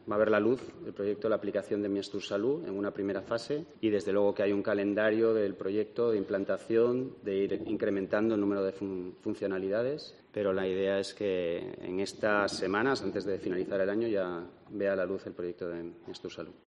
En Asturias verá la luz "antes de que termine el año", según ha anunciado el Director General de Seguridad y Estrategia Digital del Gobierno asturiano, Javier Fernández, durante la comparecencia en la Junta General del Principado para detallar las cuentas de 2023 de la consejería de Presidencia.